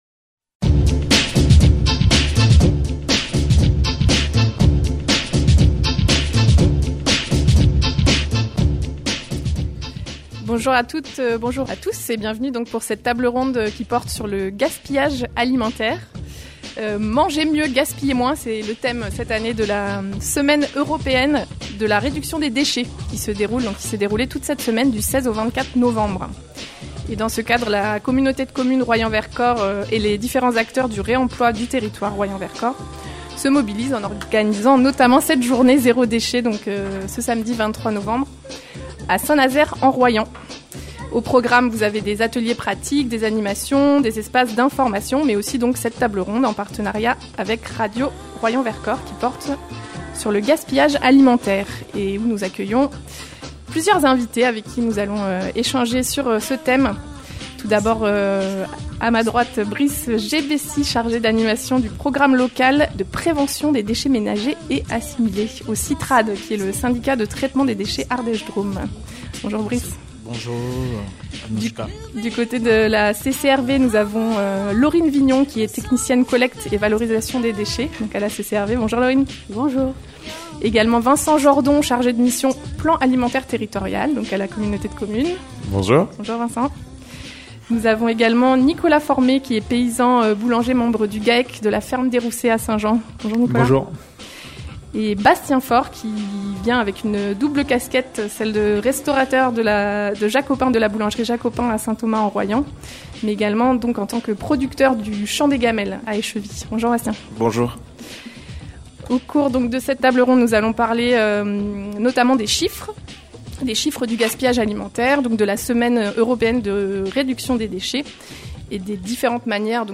Mieux manger, gaspiller moins : une table ronde sur le gaspillage alimentaire
Au programme : des ateliers pratiques, des animations, des espaces d’information, mais aussi une table ronde, en partenariat avec Radio Royans Vercors, sur le gaspillage alimentaire.